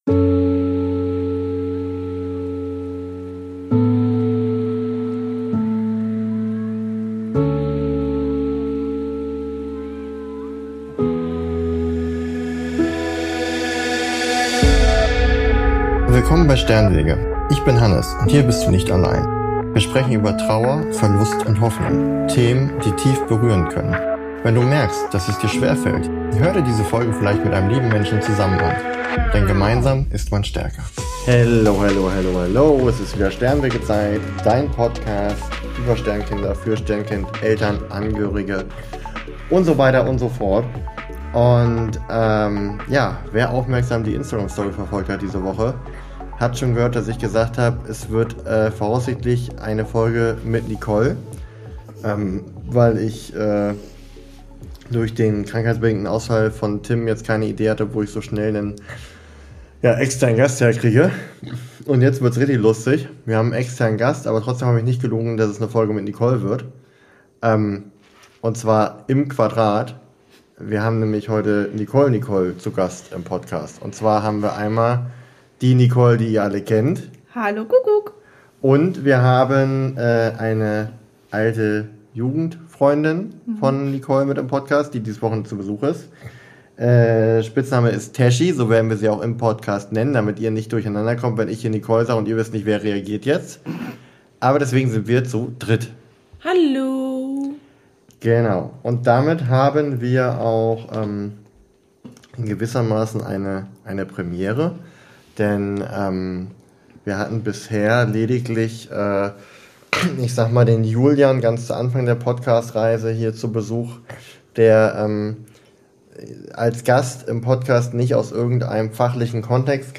Ehrlich, roh, nah – und voller Menschlichkeit.